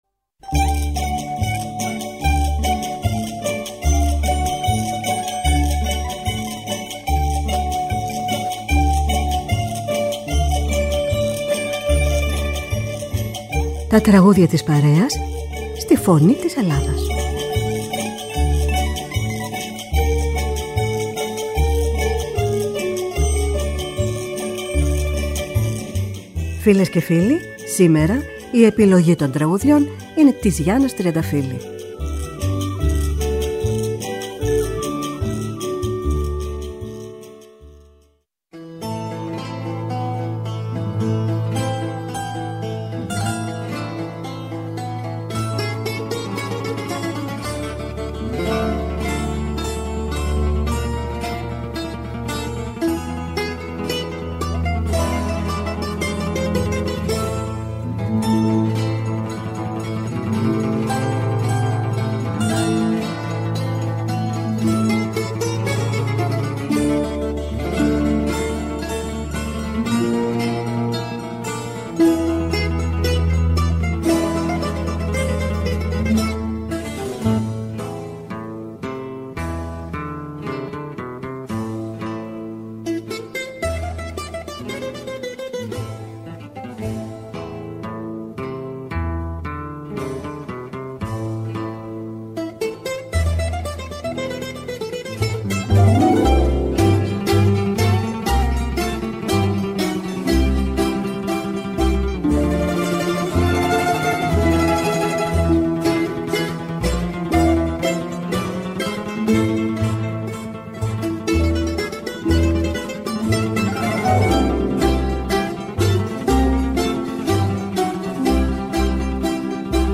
Με μουσικές από την Ελλάδα και τον κόσμο.